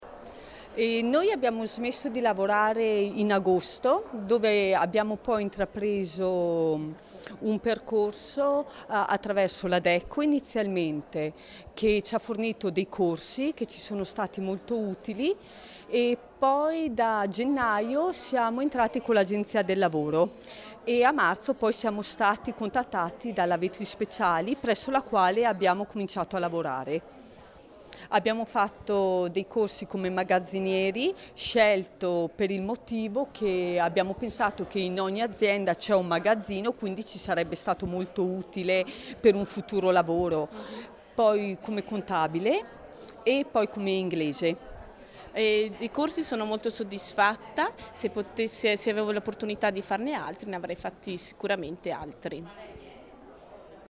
Intervista_operaie_MP3_256K.mp3